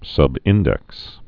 (sŭb-ĭndĕks)